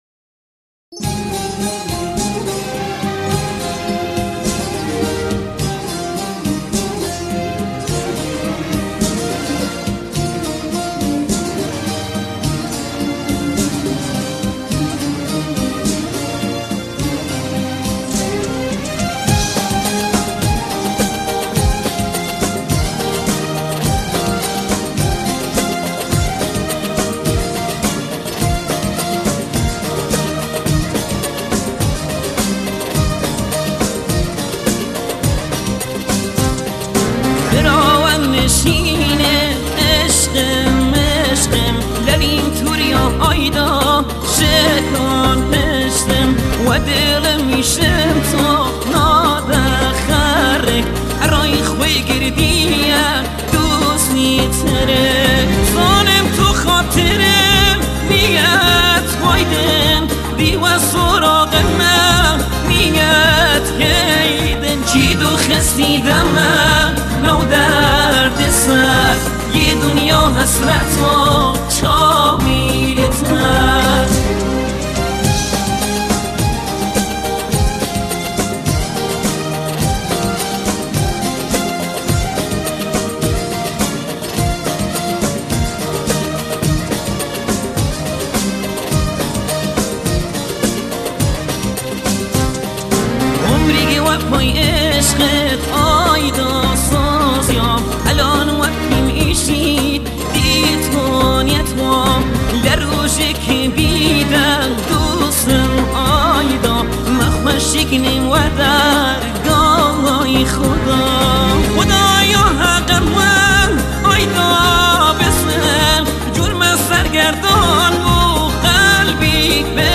آهنگ کردی قدیمی آهنگ کردی غمگین آهنگ های پرطرفدار کردی